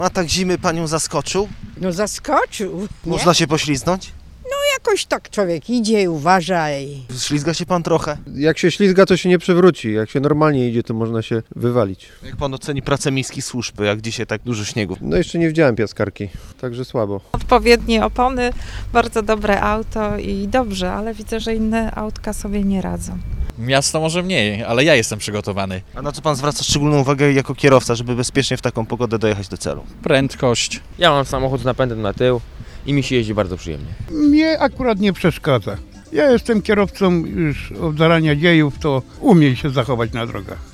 Przechodnie muszą ostrożnie poruszać się po chodnikach, a kierowcy zwracać szczególną uwagę na prędkość i śliską nawierzchnię. Zapytaliśmy mieszkańców, jak dziś radzą sobie z kapryśną pogodą: